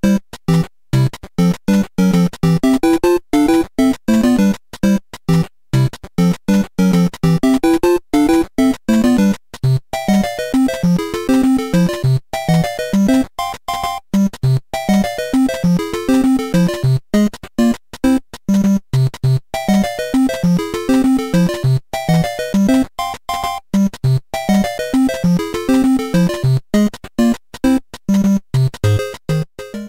With its warm NES style graphics and immersive 8-bit music